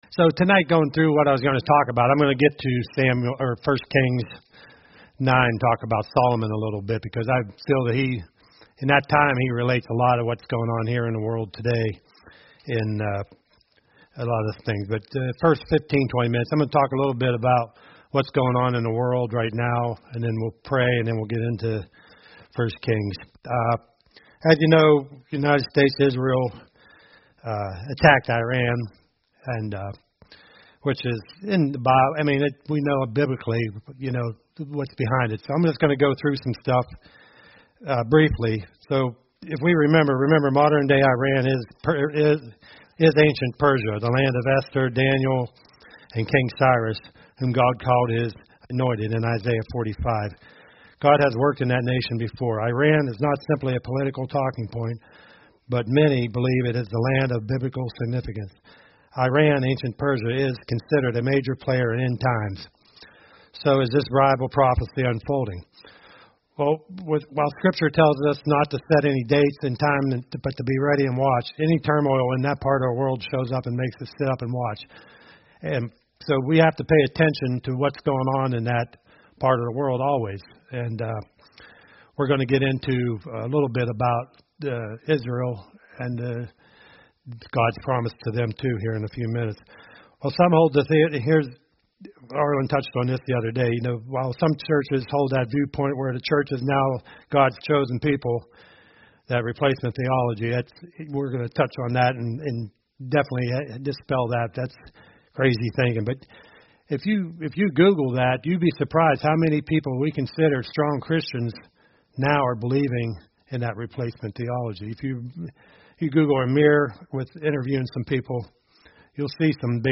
A message from the topics "Guest Speakers."